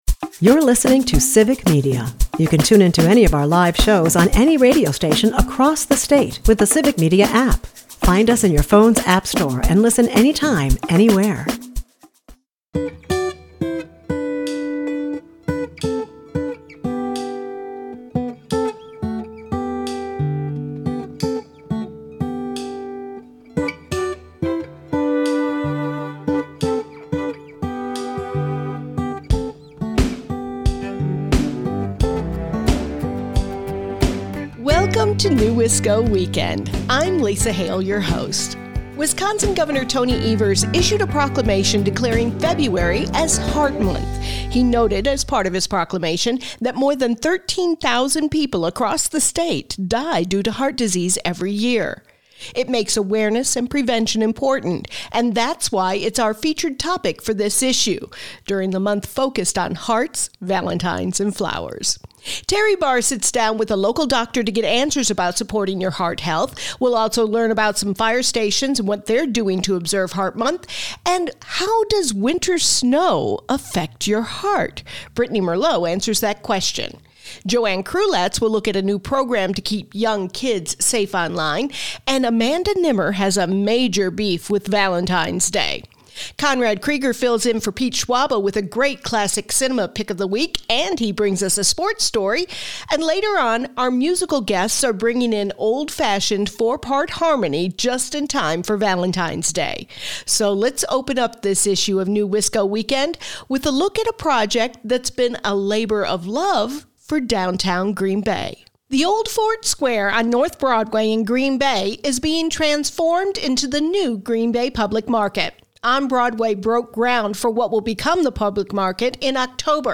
NEWisco Weekend is a news magazine-style show filled with conversations and stories about issues, entertainment, and culture making the Fox Valley, Green Bay and beyond -- a rich, unique area to call home.